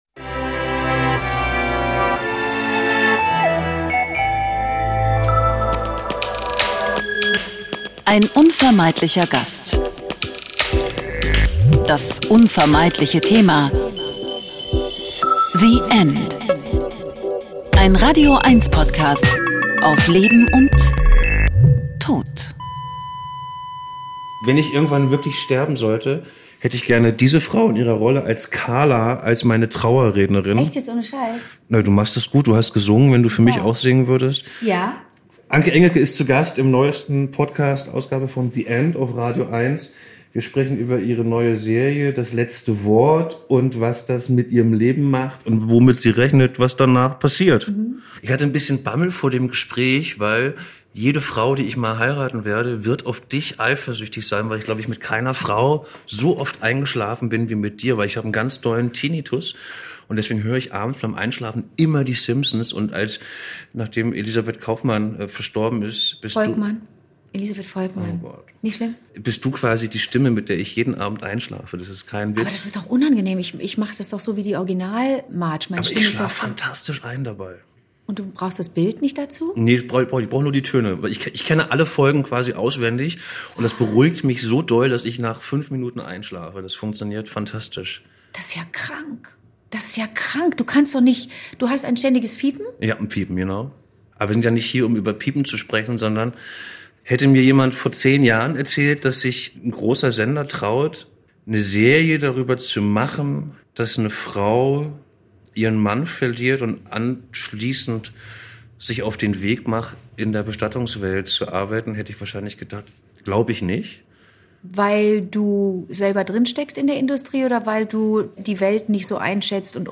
Ein Gespräch über Schockmomente, Sprachlosigkeit, Zuhören können, Angst und Hoffnung.